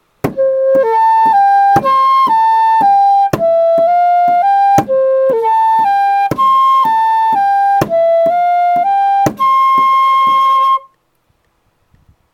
Sif Chant Mantras and Yantras Chant Melody audio (no words) Sif Reynidis Minnis-Veig, Sif Sumbeldis Minnis-Horn Sif.